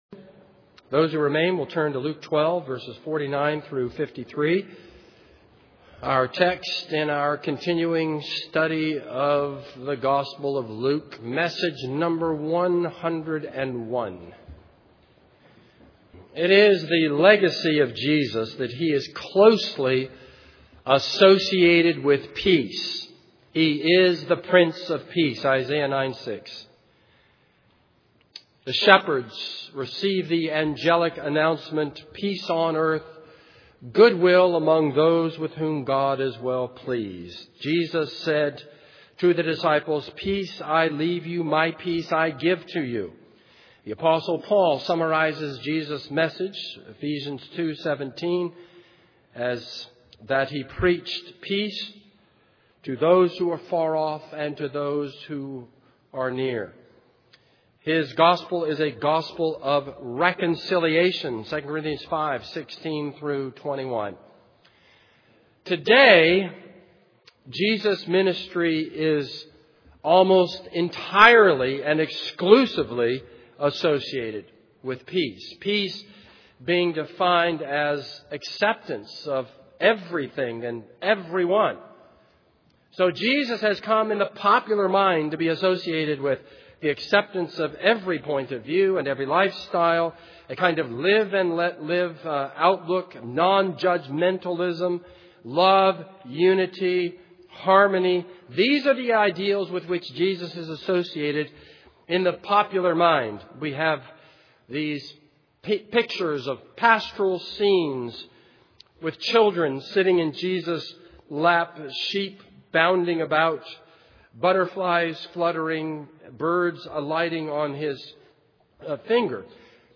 This is a sermon on Luke 12:49-53.